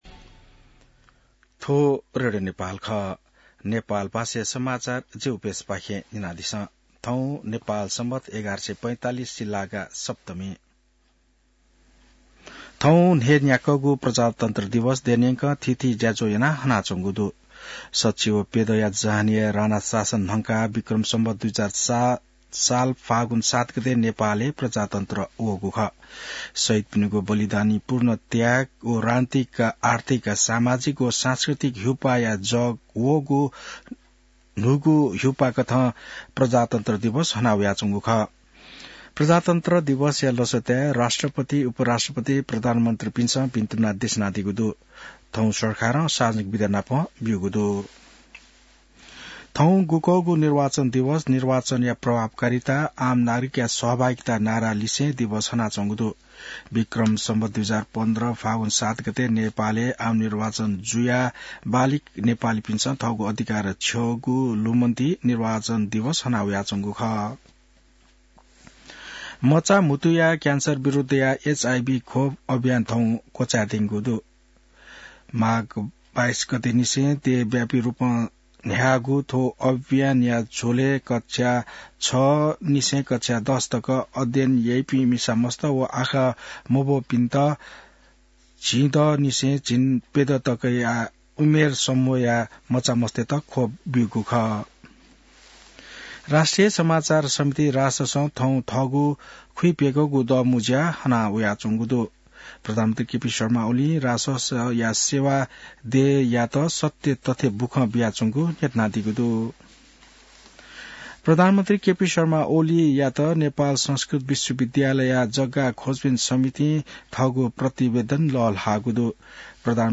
नेपाल भाषामा समाचार : ८ फागुन , २०८१